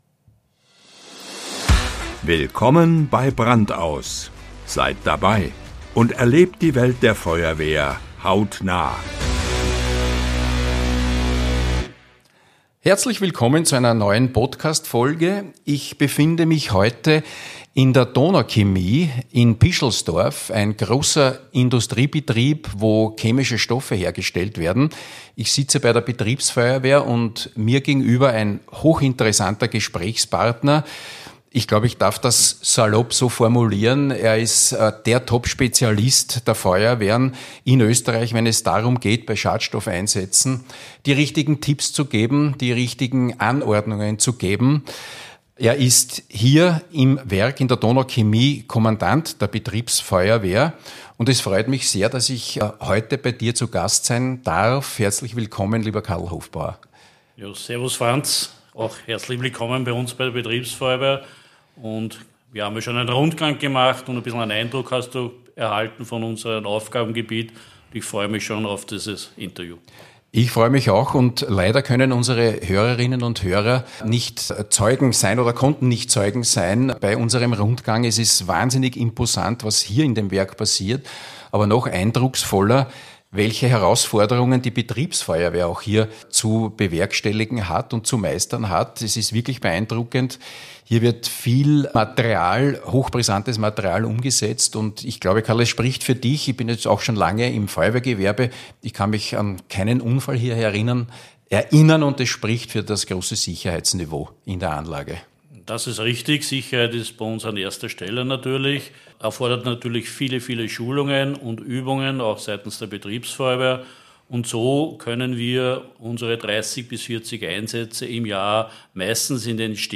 Beschreibung vor 1 Jahr In unserer neuesten Podcast-Folge tauchen wir tief in die Welt der Schadstoffeinsätze ein und sprechen mit einem echten Experten auf diesem Gebiet